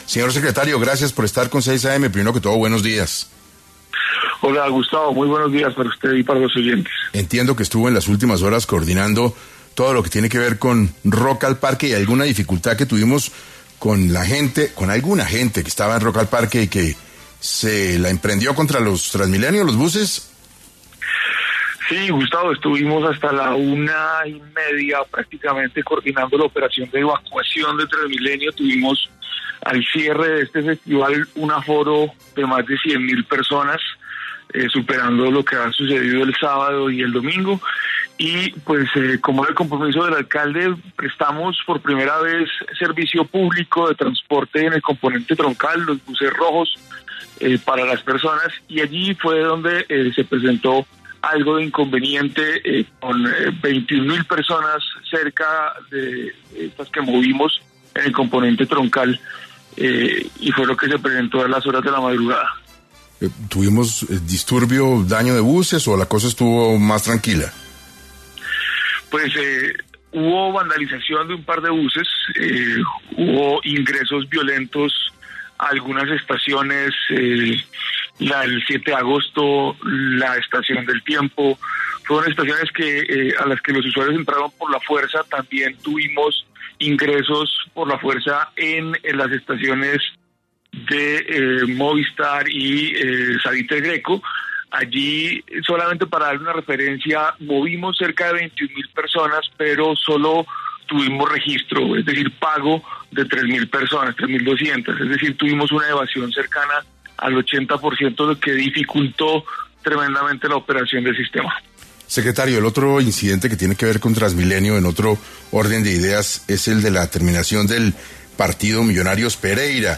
En 6AM de Caracol Radio estuvo Miguel Silva, Secretario General Alcaldía de Bogotá, quien habló sobre cuáles son las soluciones que plantean para los afectados por la falta de transporte después del partido Millonarios-Pereira y el por qué se dio esta afectación.
Miguel Silva, Secretario General Alcaldía de Bogotá